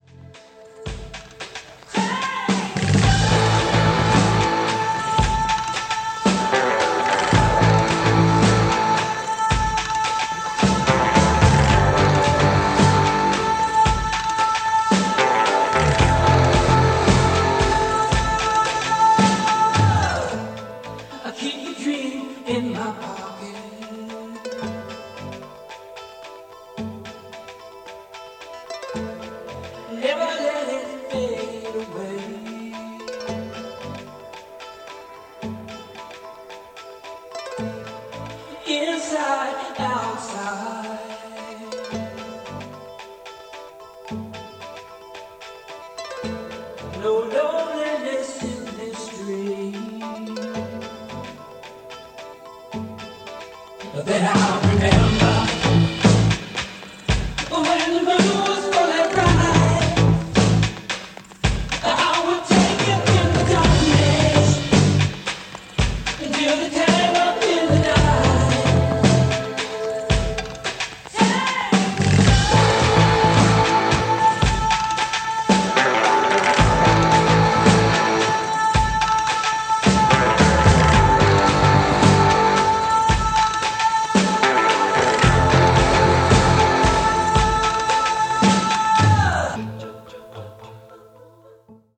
Tascam-112B-Test-Recording.mp3